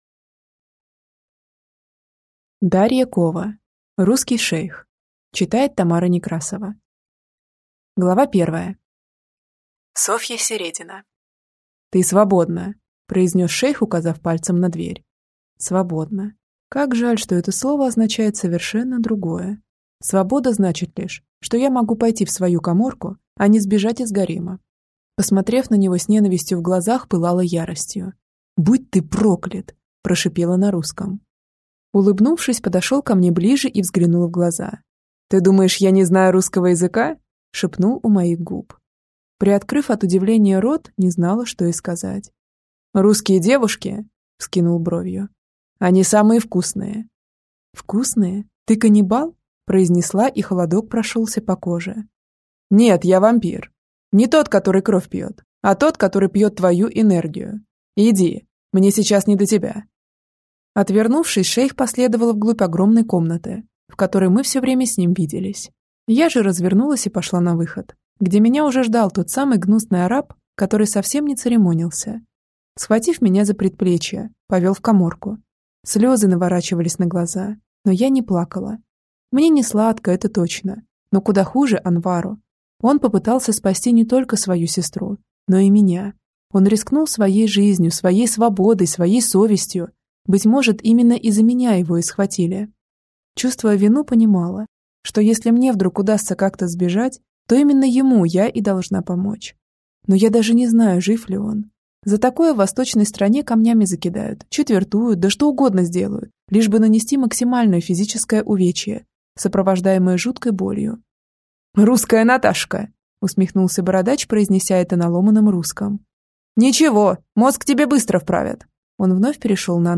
Аудиокнига Русский шейх | Библиотека аудиокниг